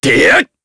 Kain-Vox_Attack3_jp.wav